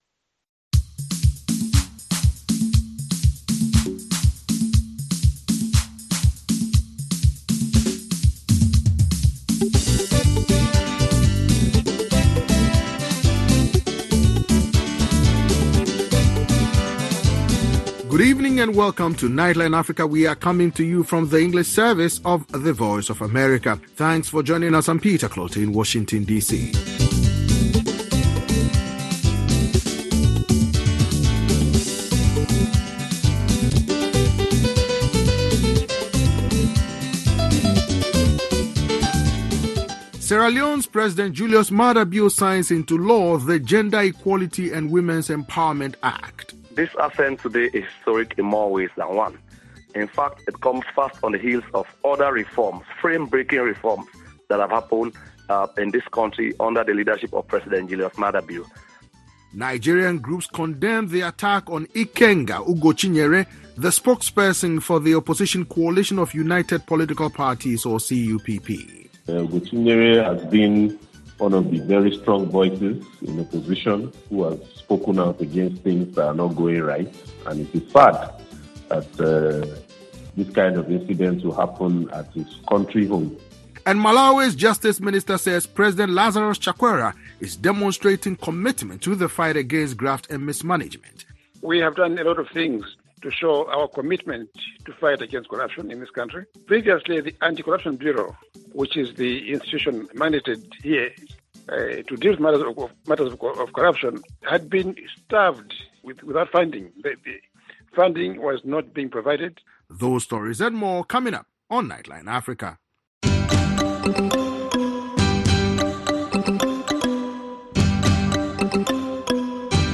Join our host and correspondents from Washington and across Africa as they bring you in-depth interviews, news reports, analysis and features on this 60-minute news magazine show.